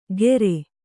♪ gere